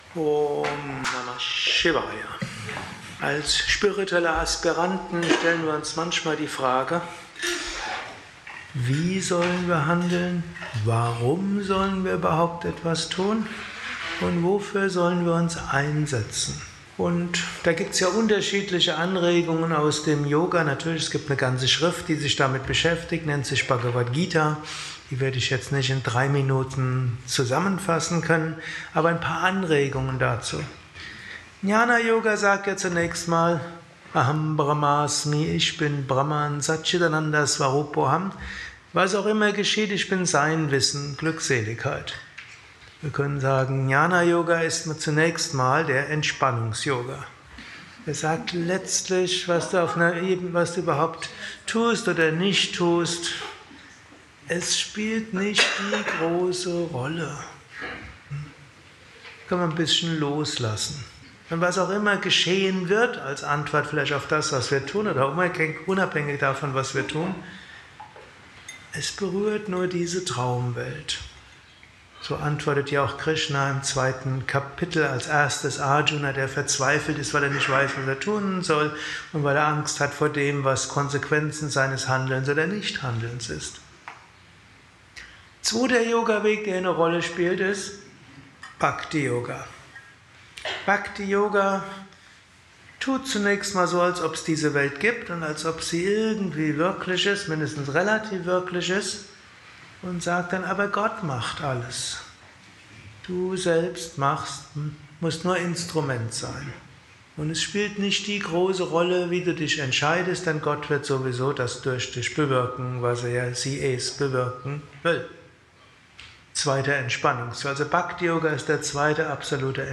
Dies ist ein kurzer Vortrag als Inspiration für den heutigen Tag